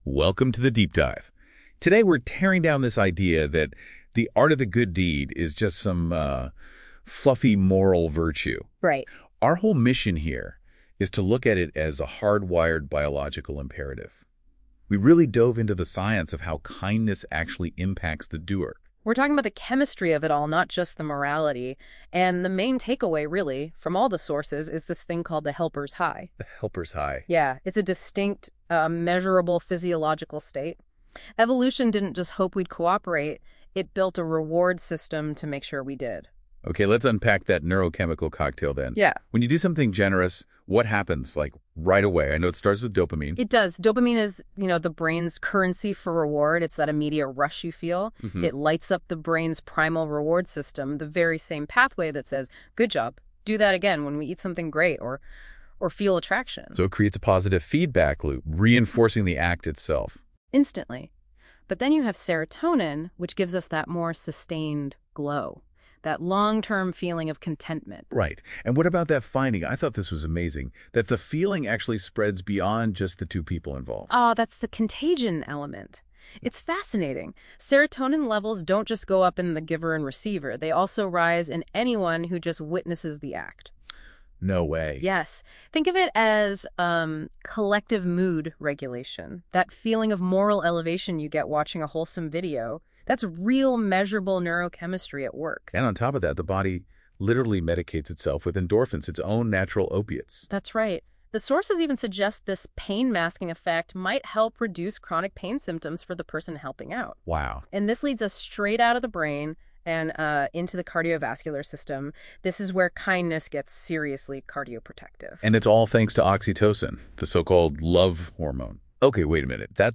To introduce this idea properly, I recorded a short audio essay called: